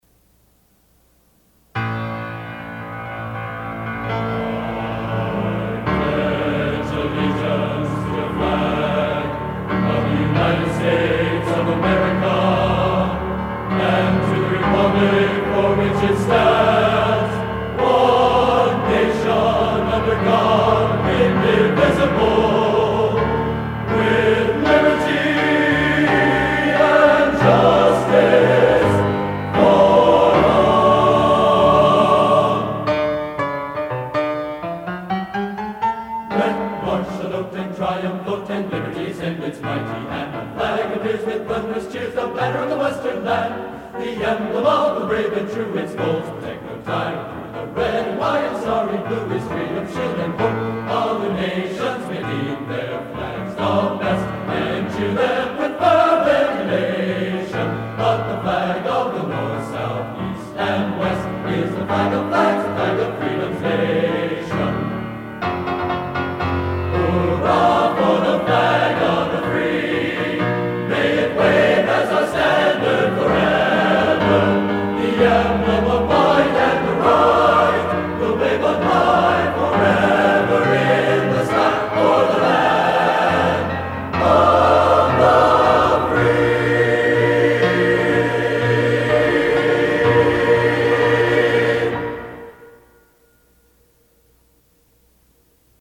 Genre: Patriotic | Type: